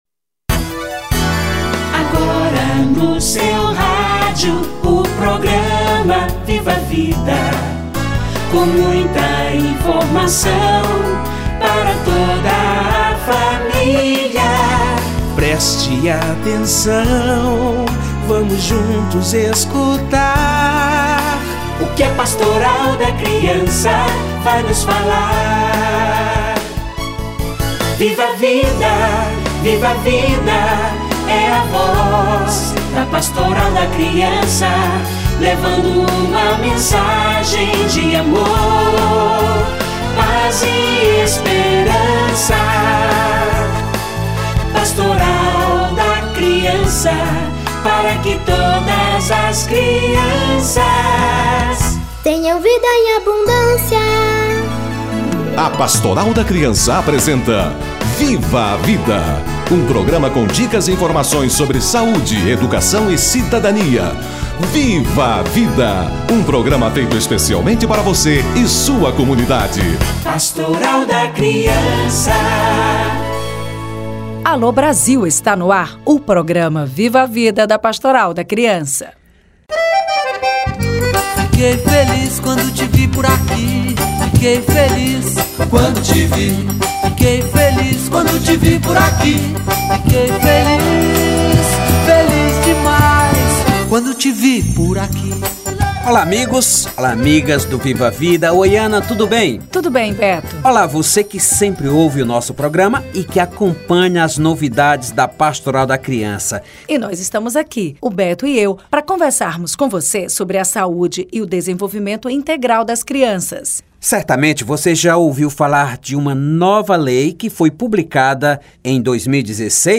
Marco Legal da Primeira Infância - Entrevista